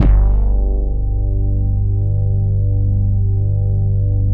23 BASS   -R.wav